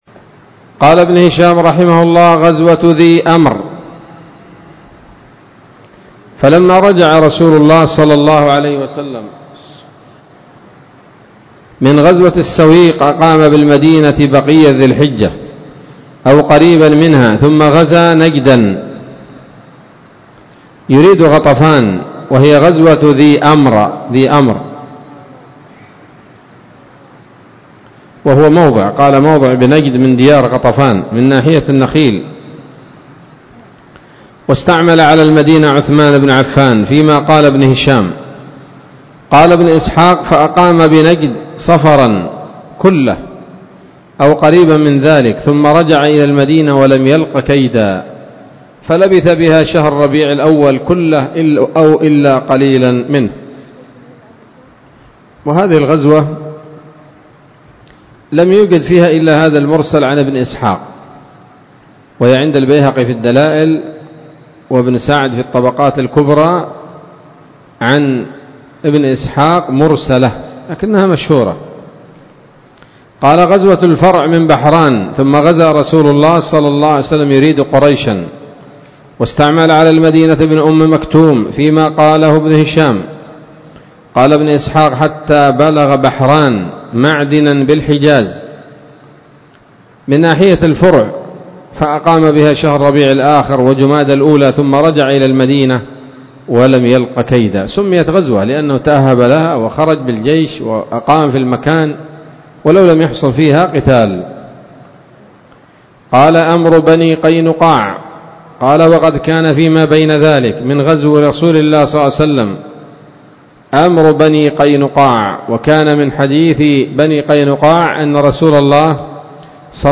الدرس الثامن والأربعون بعد المائة من التعليق على كتاب السيرة النبوية لابن هشام